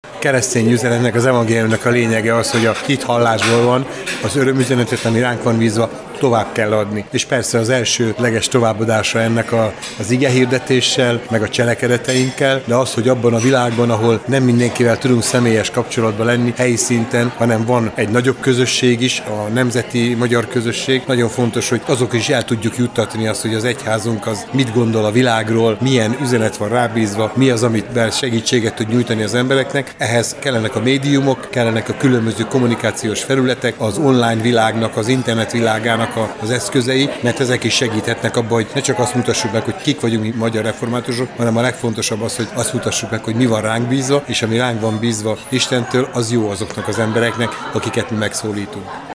balogh_zoltan_puspok_hirebe.mp3